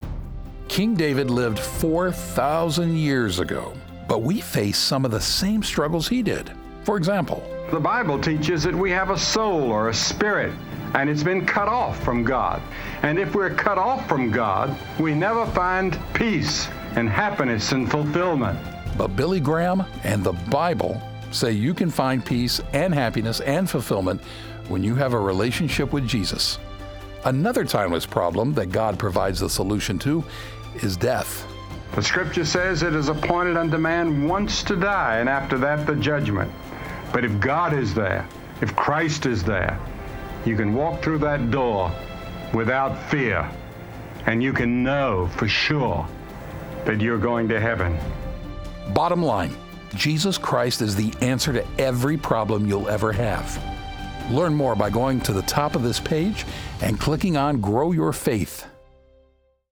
In this one-minute message, Billy Graham explains what those problems are and how we can solve them.